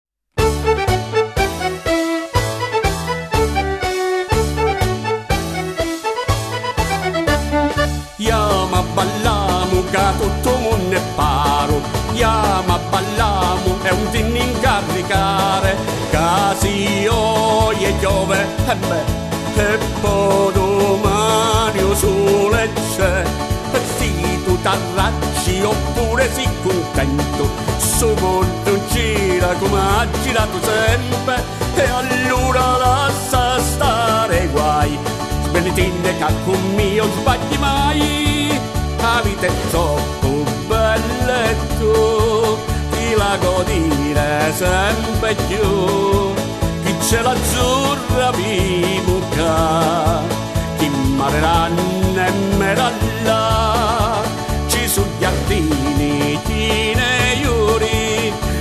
Tarantella